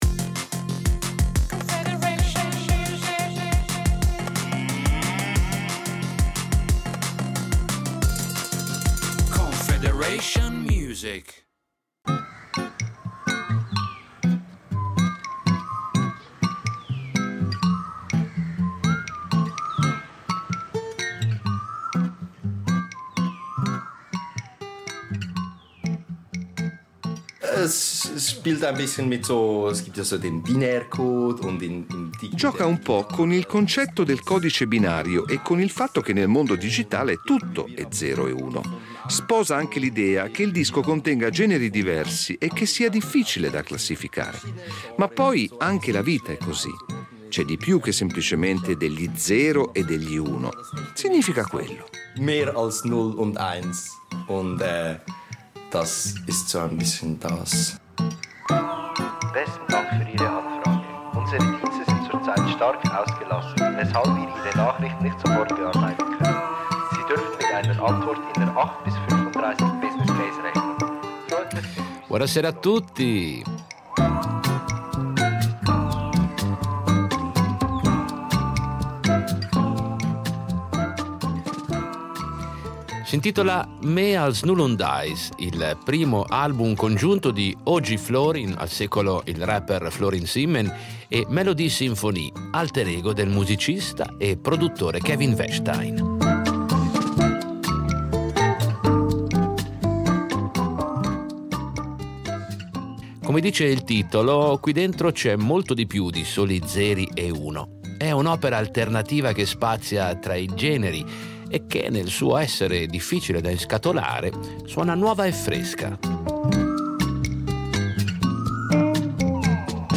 Musica pop